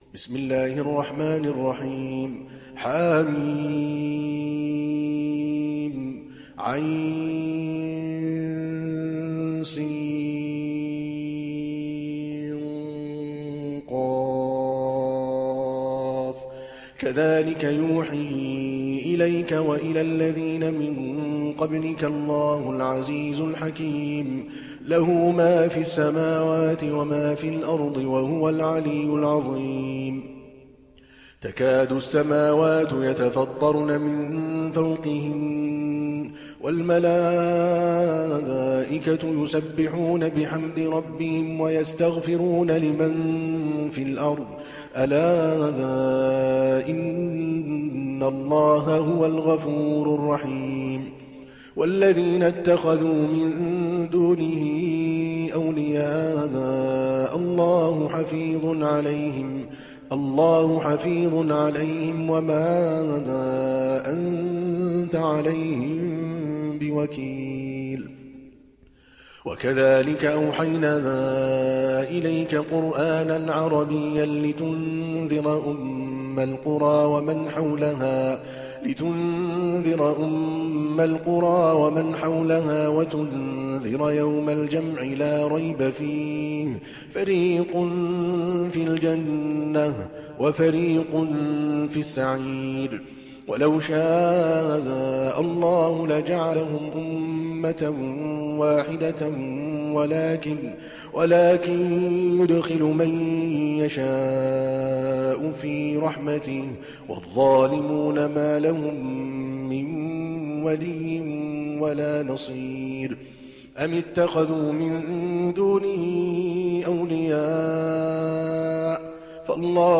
حفص از عاصم